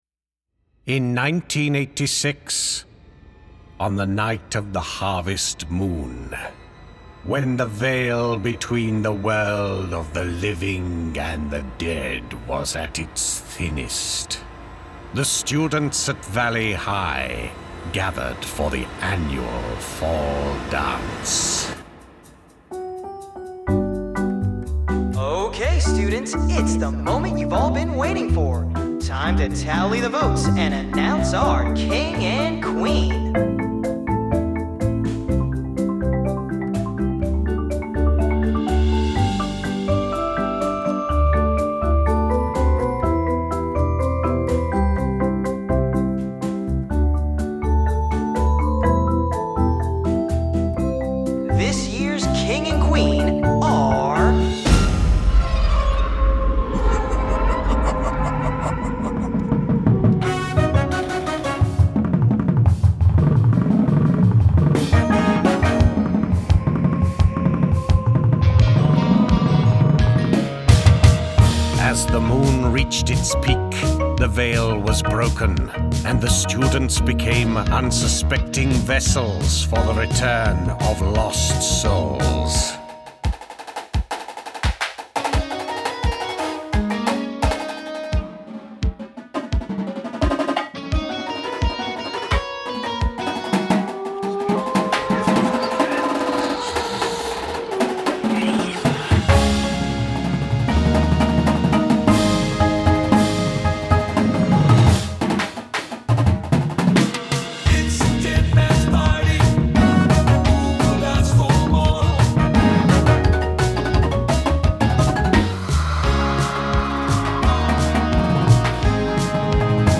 Indoor Percussion Shows
• Snares
• Tenors
• 4/5 Basses
• Cymbals
Front Ensemble
• 3/4 Marimbas
• Xylo/Crotales/Bells
• 3/4 Vibes
• 3 Synths
• Upright Bass
• Timpani
• Drumset